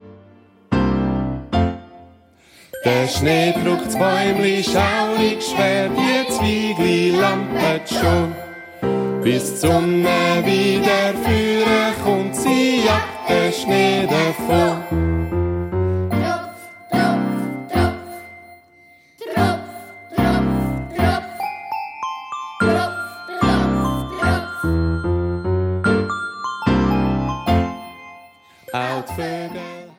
Alte Schweizer Weihnachtslieder sanft renoviert